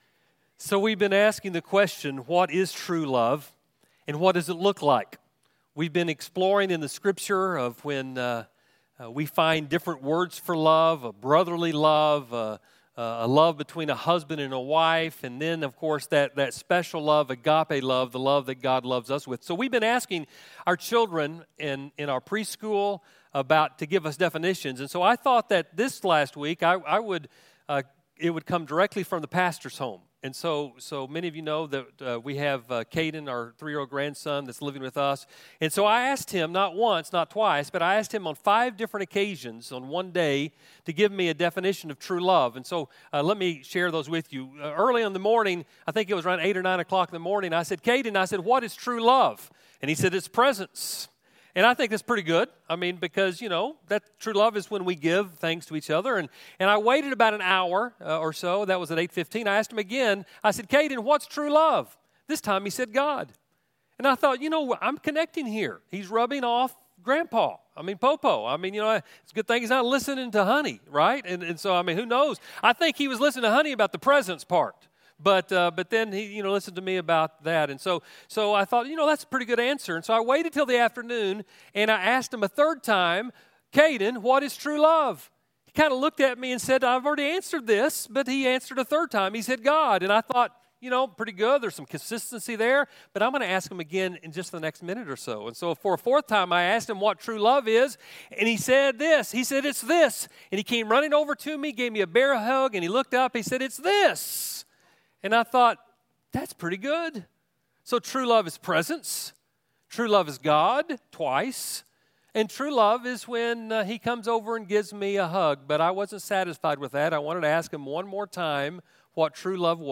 Message: “Orphan Sunday 2023” from Panel
A message from the series "Orphan Sunday."